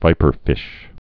(vīpər-fĭsh)